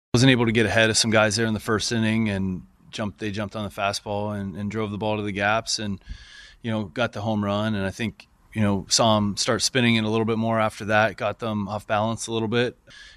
Manager Don Kelly says Andrew Heaney continues to struggle to command his pitches.